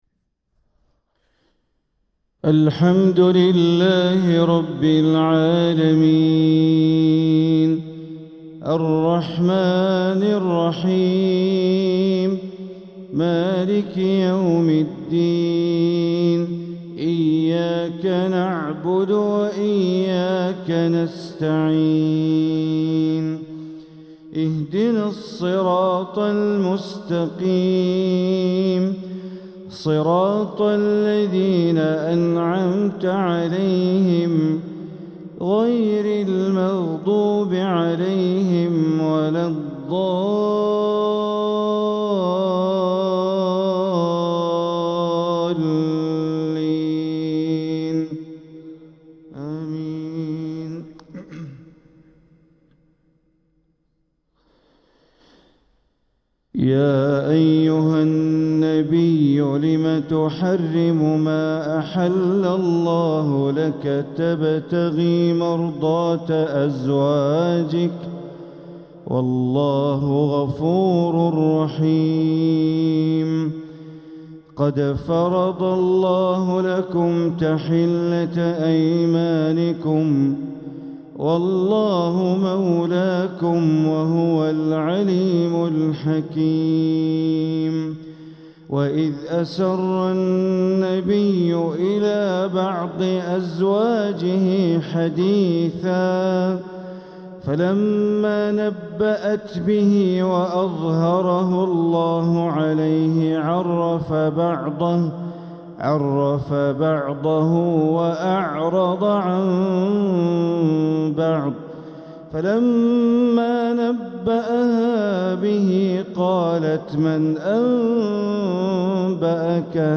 تلاوة لسورة التحريم | فجر السبت ١٩ ربيع الآخر ١٤٤٧ > 1447هـ > الفروض - تلاوات بندر بليلة